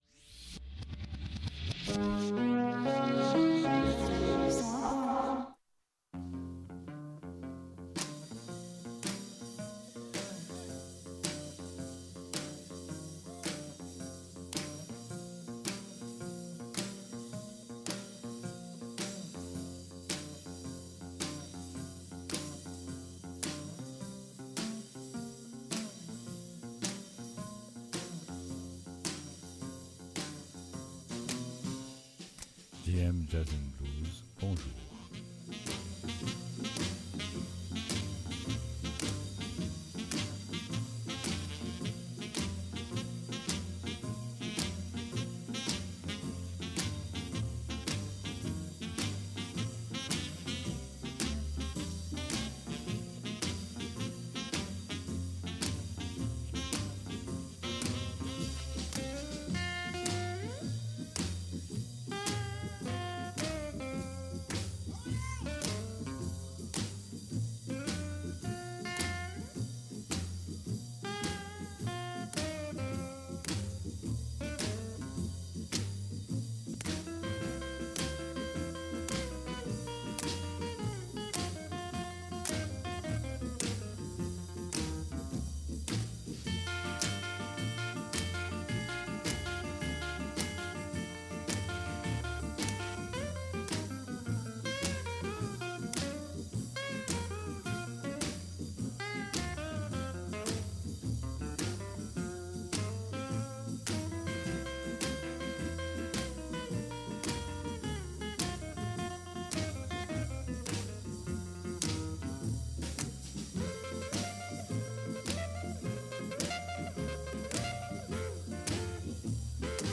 The Chicago-Dixieland Style Bands
cornet
trombone
clarinette
saxophone…